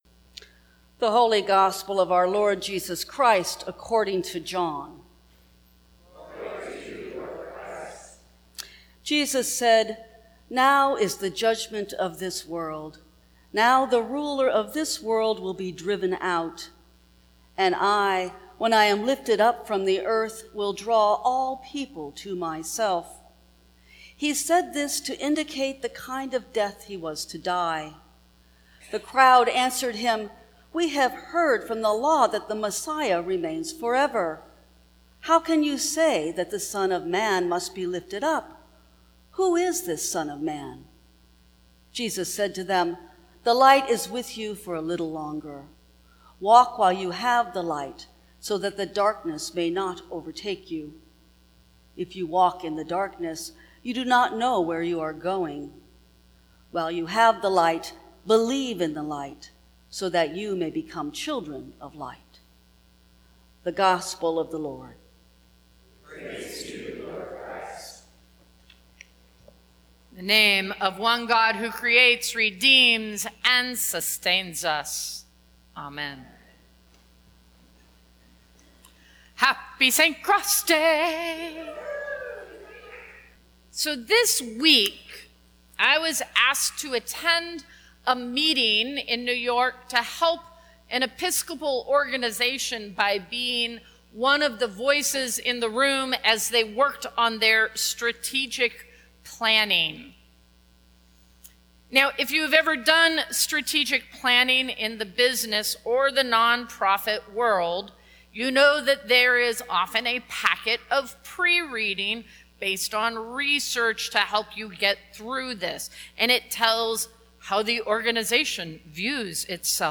Sermons from St. Cross Episcopal Church What Does That Mean?